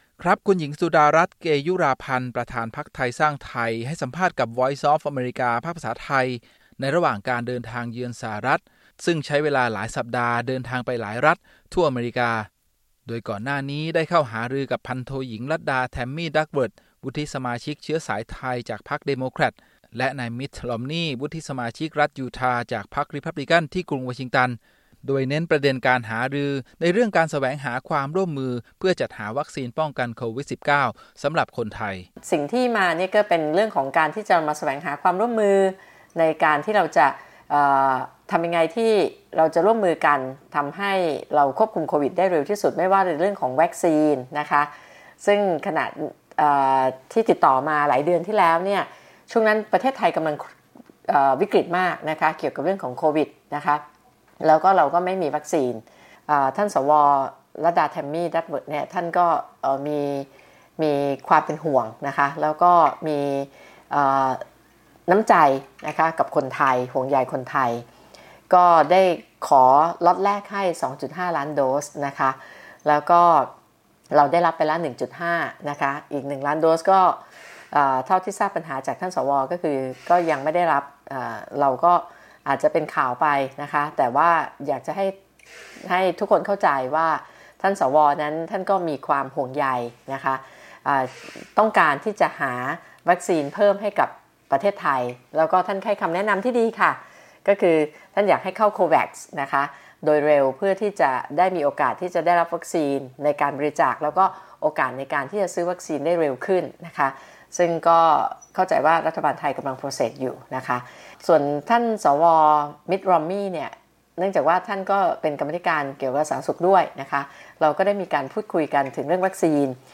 Sudarat Interview